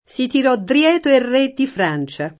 dietro [dL$tro] prep. e avv. — ant. drieto [drL$to]: si tirò drieto el re di Francia [
Si tirq ddrL$to el r% ddi fr#n©a] (Machiavelli) — antiq. o pop. tosc. dreto [